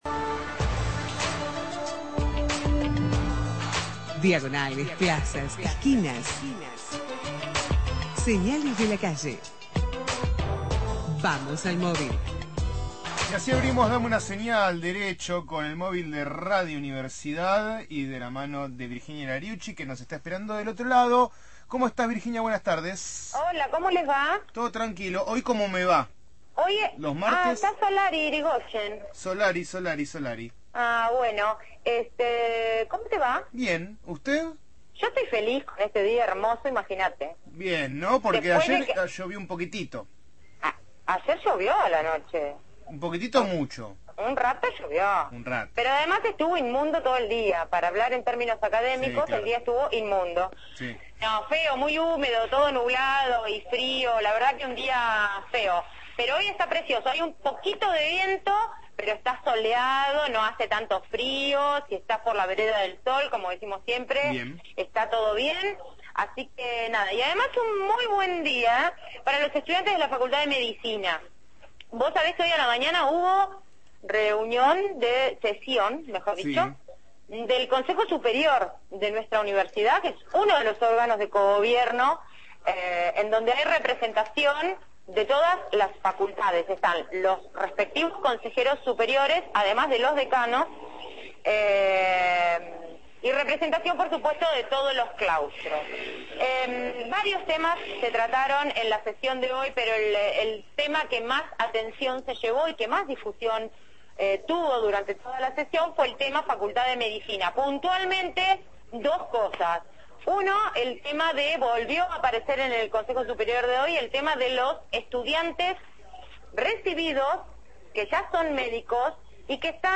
MÓVIL/ Medidas del Consejo Superior sobre la situación en Medicina – Radio Universidad